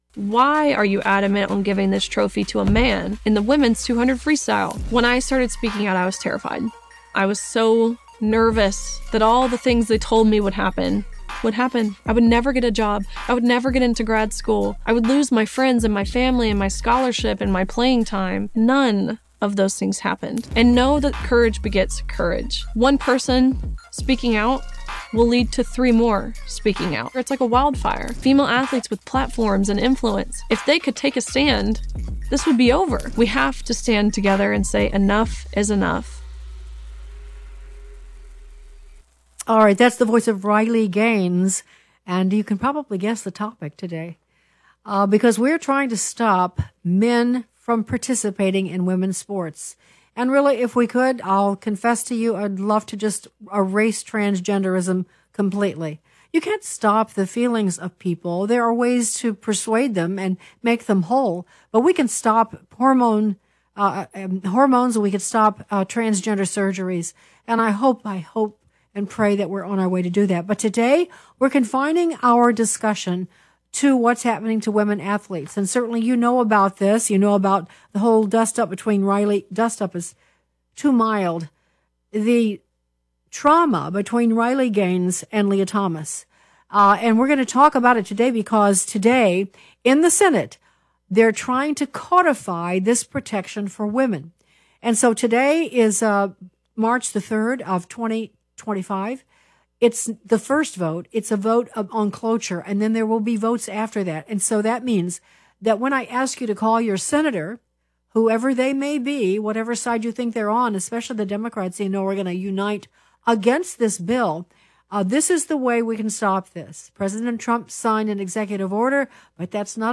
Igra pripoveduje, kako se je kmet Gašper poskušal izkopati iz težav in kaj vse je moral storiti, da je rešil družino.